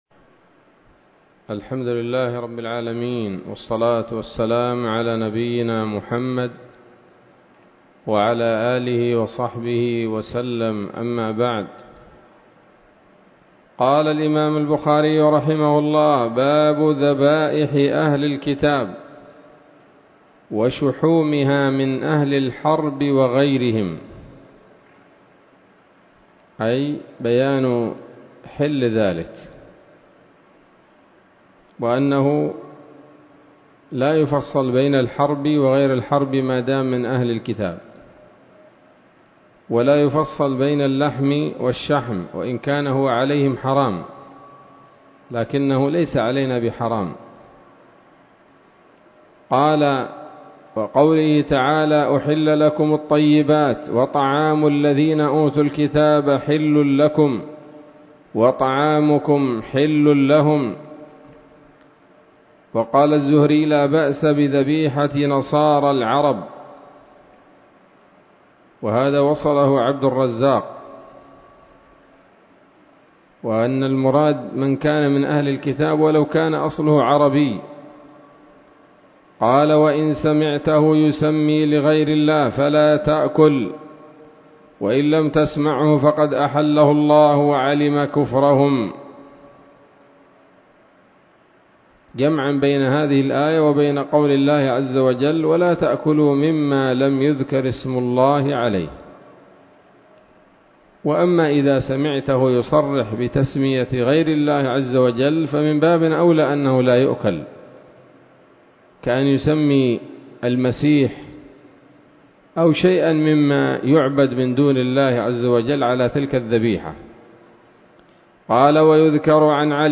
الدرس الثامن عشر من كتاب الذبائح والصيد من صحيح الإمام البخاري